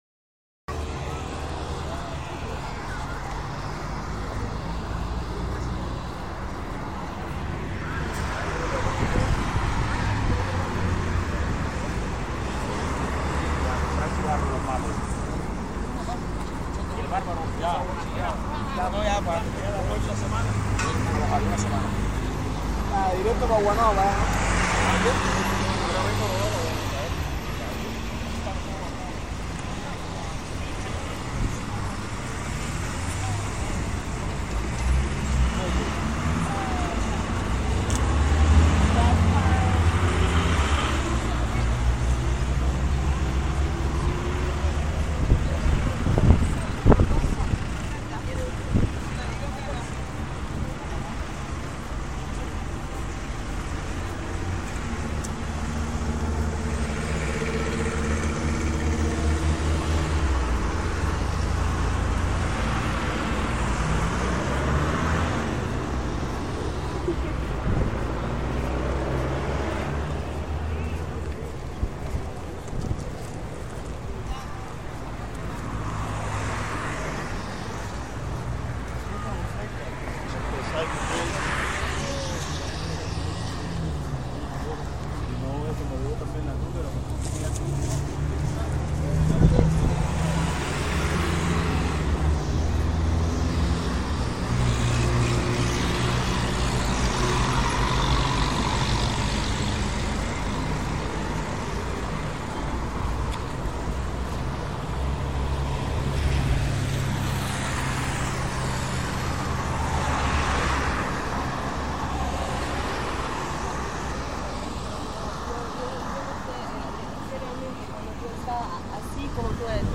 Paisaje Sonoro del Malecón de La Habana Cuba (Toma 1)
Quedándome quieta en el sitio. Estoy ubicada 1 km antes de llegar a la calle 23, sostuve el celular en mi mamo mientras estuve reclinada sobre el muro del malecón. Lo grabe a las 09:07 de la noche en Habana (08:07 pm hora de Mexico que es la que registró mi celular).... ese día el mar estaba bastante picado y corría mucho aire...
22 de julio de 2014 Lugar: Malecón de La Habana, Cuba. Equipo: Telefono Samsung Galaxy Young Fecha: 2015-07-13 15:45:00 Regresar al índice principal | Acerca de Archivosonoro